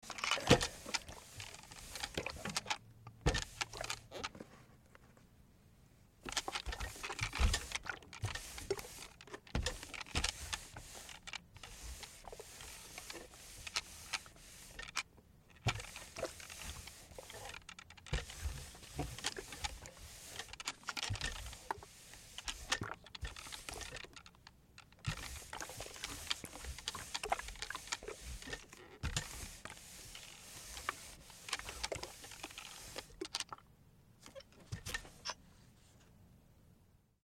Звуки утюга
Звук утюга при глажке вещей (есть отдельный пост на эту тему)